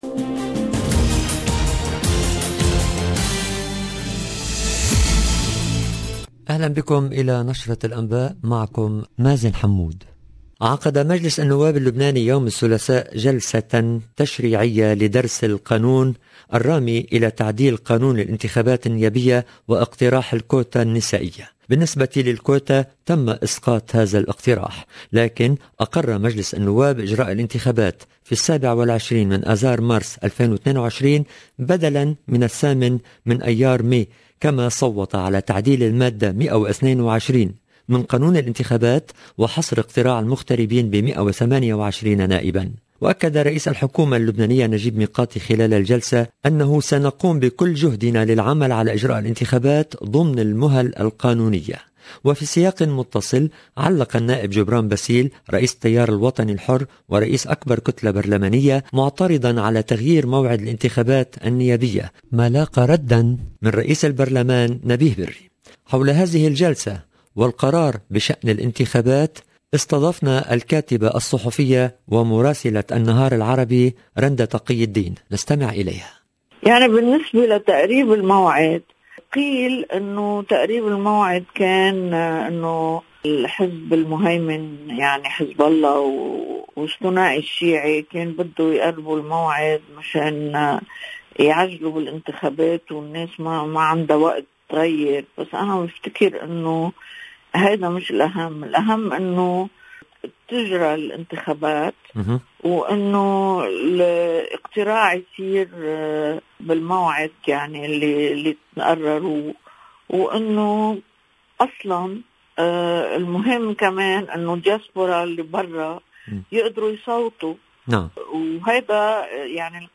LE JOURNAL DU SOIR EN LANGUE ARABE DU 19 OCTOBRE 2021
EDITION DU JOURNAL EN LANGUE ARABE